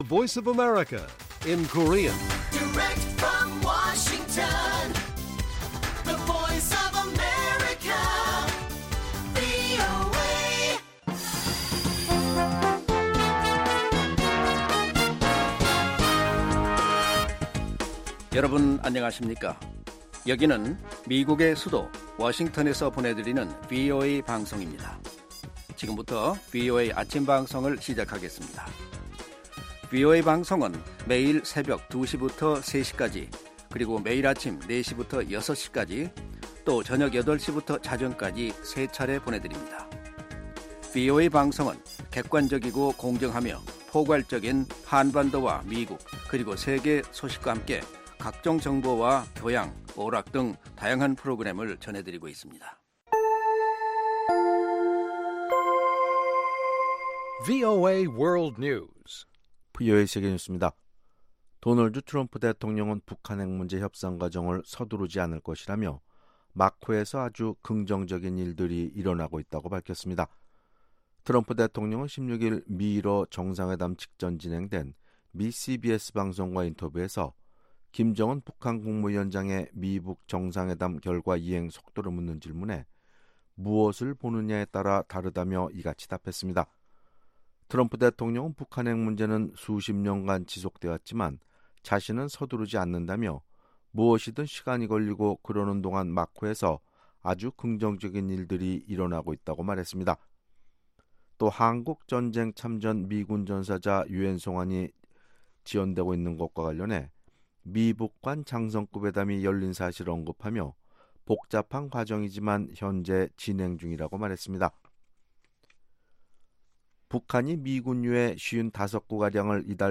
세계 뉴스와 함께 미국의 모든 것을 소개하는 '생방송 여기는 워싱턴입니다', 2018년 7월 18일 아침 방송입니다. ‘지구촌 오늘’에서는 핀란드 헬싱키에서 진행된 도널드 트럼프 미국 대통령과 블라미디르 푸틴 러시아 대통령의 첫 단독 회담에 혹평이 몰리고 있다는 소식, ‘아메리카 나우’에서는 러시아 여성이 미국 안에서 신고하지 않고 러시아 정부를 위해 일한 혐의로 기소됐다는 이야기 전해드립니다.